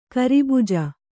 ♪ karibuja